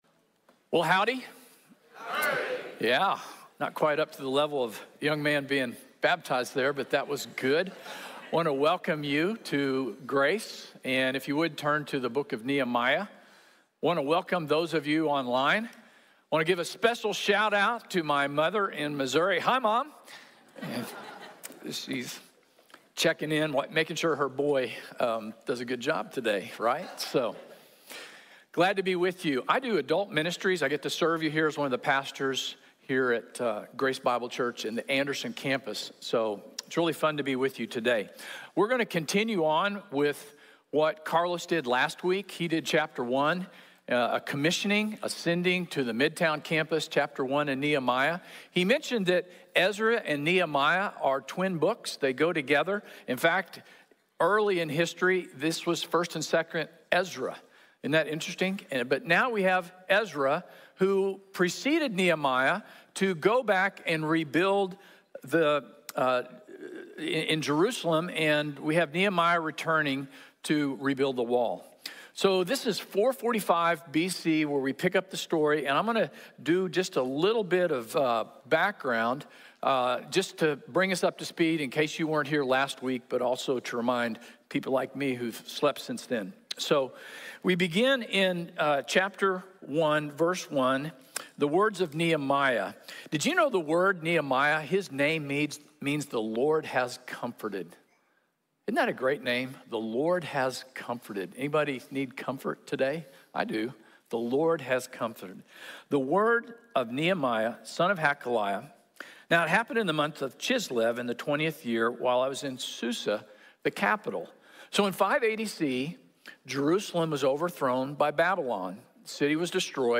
Teamwork | Sermon | Grace Bible Church